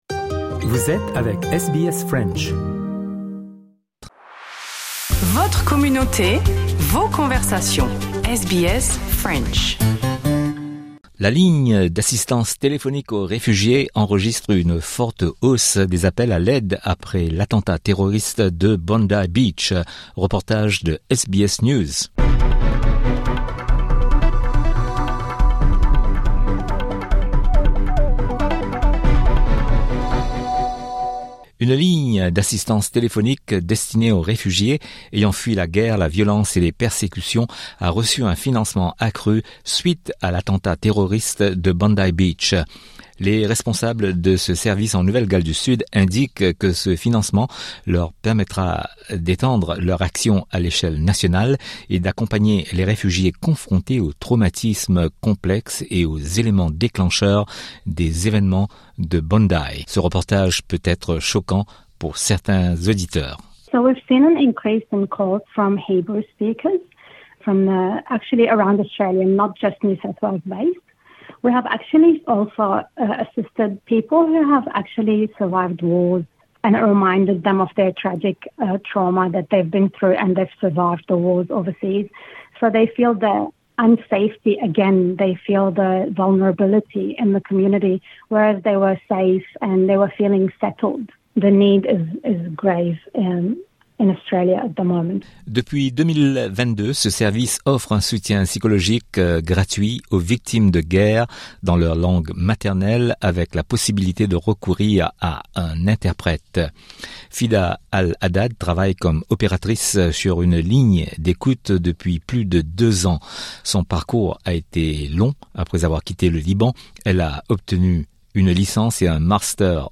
La ligne d'assistance téléphonique aux réfugiés enregistre une forte hausse des appels à l'aide après l'attentat terroriste de Bondi. Ce reportage peut être choquant pour certaines personnes.